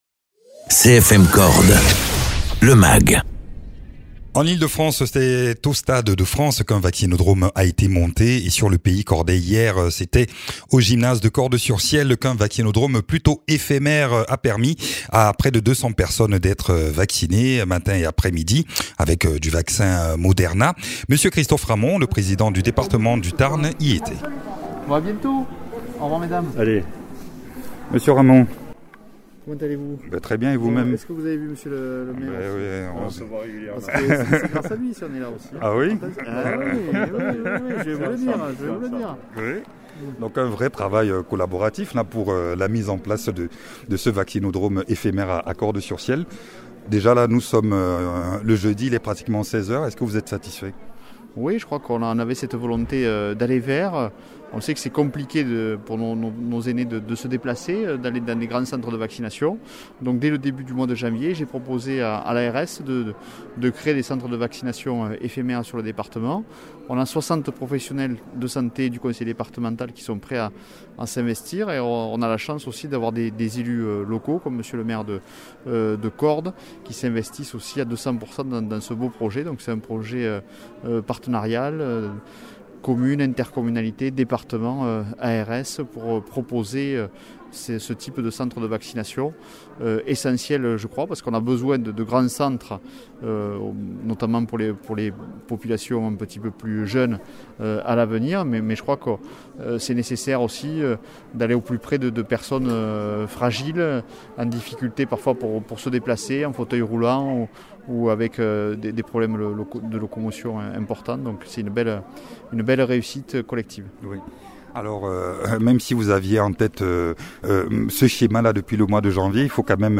L’objectif étant de se rapprocher notamment des personnes les moins mobiles comme nos aînés. Reportage à Cordes-sur-ciel où près de 200 personnes ont reçu leur première dose de vaccins.
Interviews